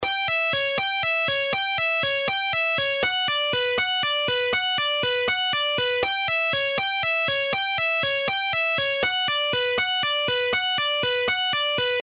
Warming up!! Very Basic Sweep Picking Arpeggios in 2 String for Absolutely Beginner : )
2-String-Basic-Sweep-Picking-Exercise-1-1-1.mp3